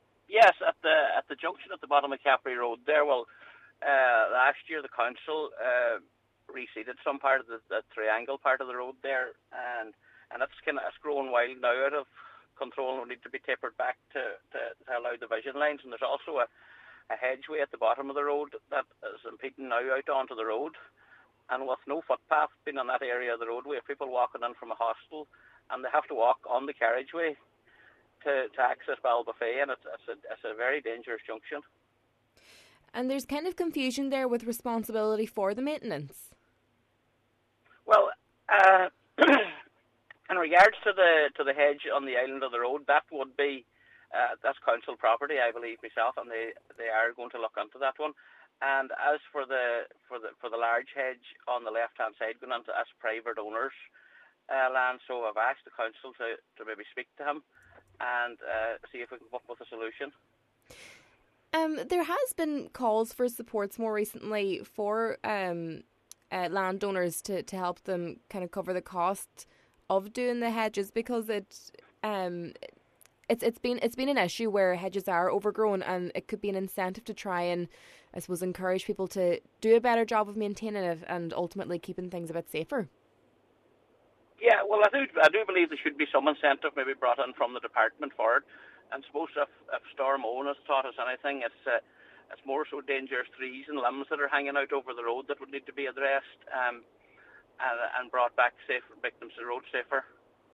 Cllr Scanlon says the junction is very busy, and action needs to be taken urgently: